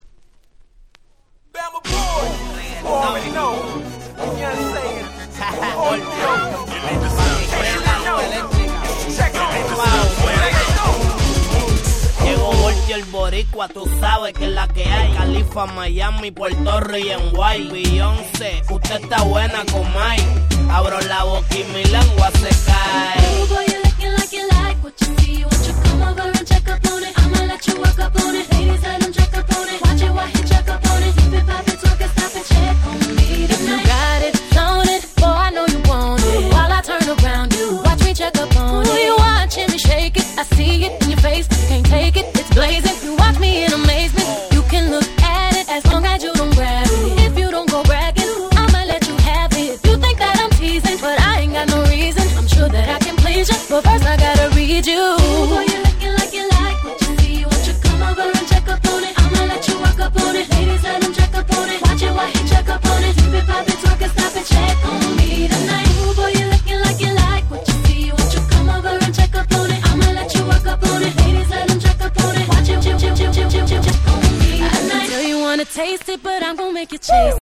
06' Super Hit R&B !!
プロモオンリーのレゲトンRemix !!